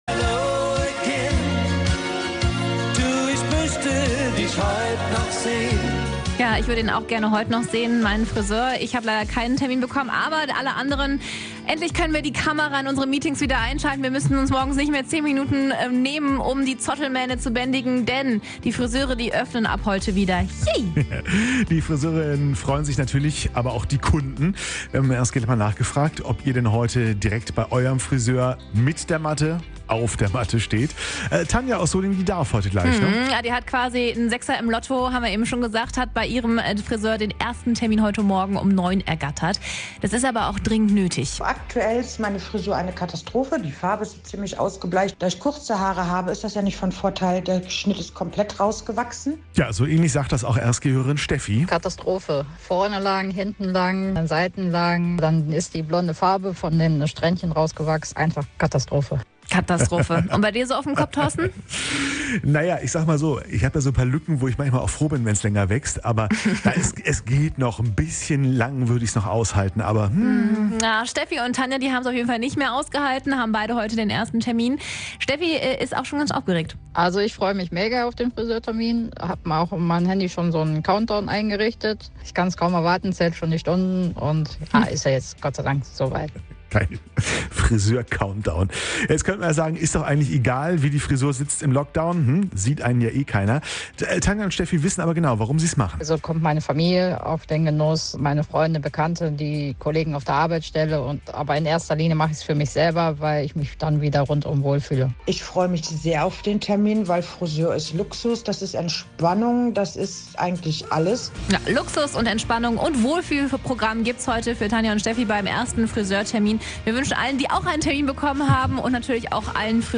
haben vorab in der Morgenshow mit ein paar von ihnen telefoniert.